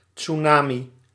Ääntäminen
IPA: /tsunami/